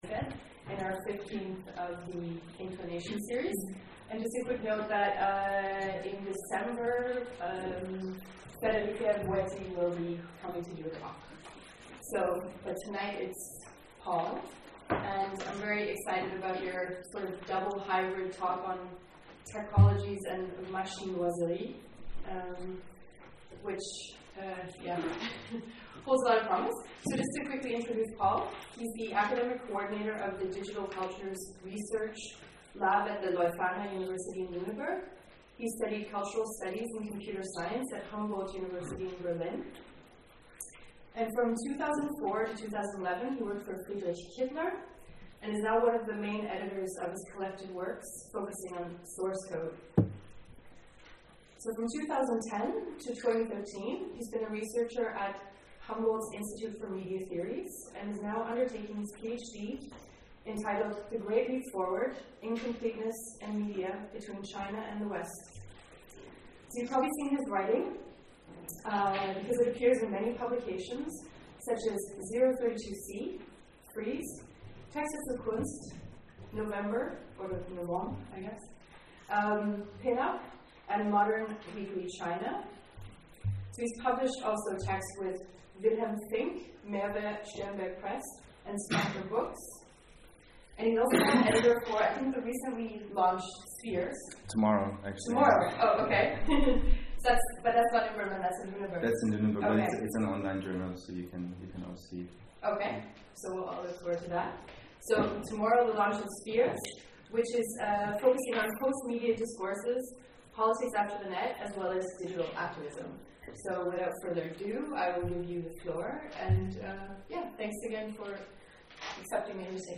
Talks Inclinations #15: Tecologies and Machinoiserie
The convergence points of nature and technology, questions of synthetic biology and ecological consciousness can be discussed against this unstable background. Inclinations: A monthly speaker series at Or Gallery Berlin, hosting philosophers, artists, curators, and…